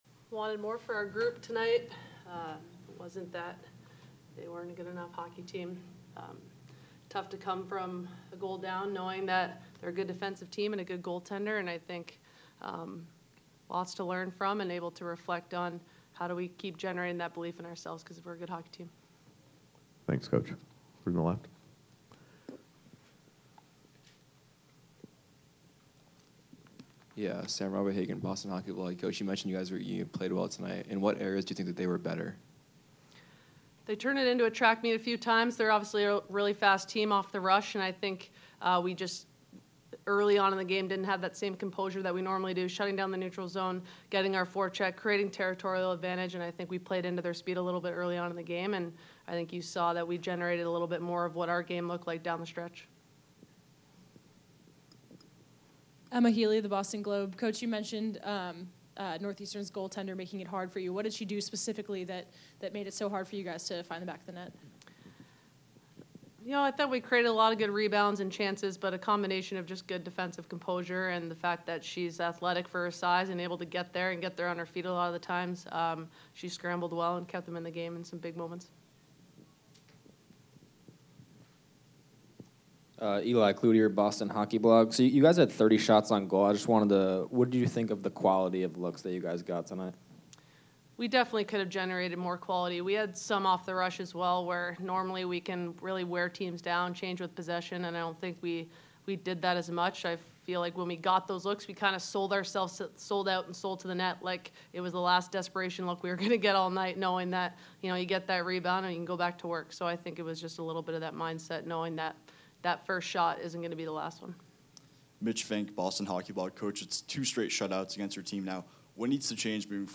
Beanpot Championship Postgame (1-21-25)